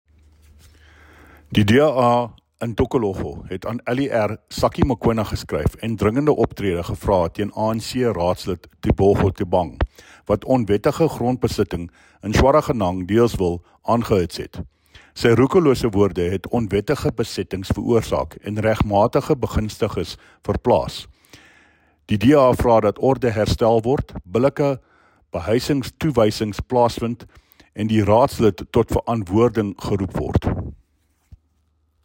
Afrikaans soundbites by David Mc Kay MPL, and